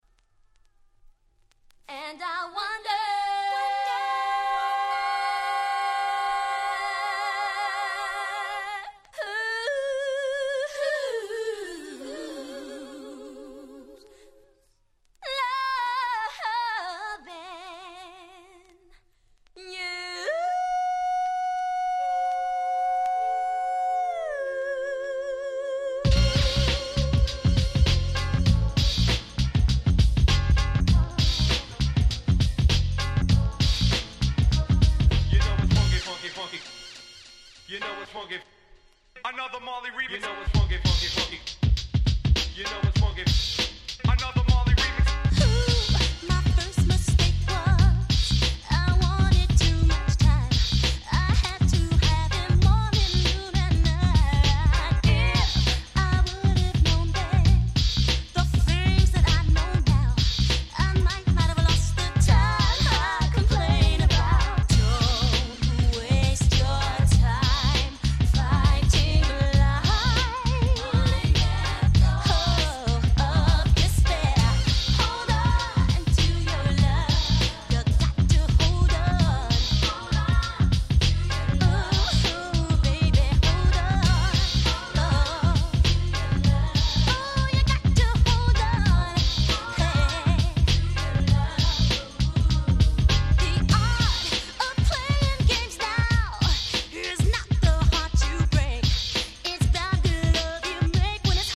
91' Remix EP !!